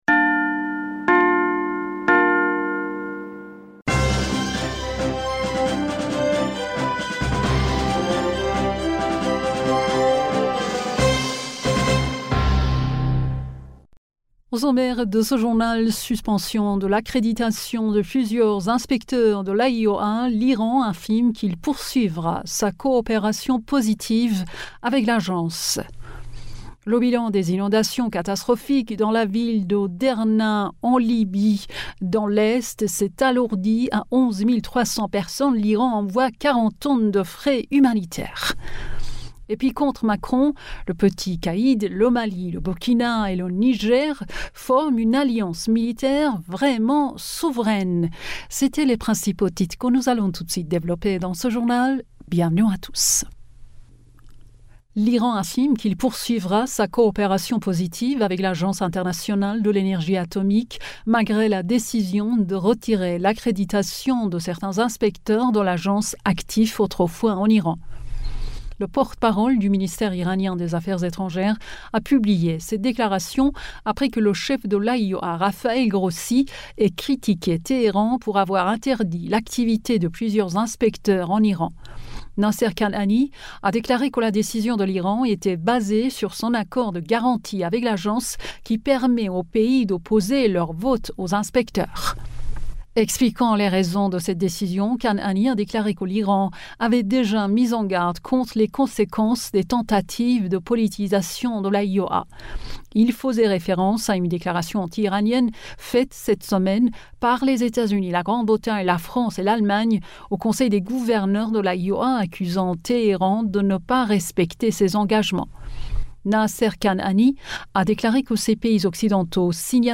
Bulletin d'information du 17 Septembre 2023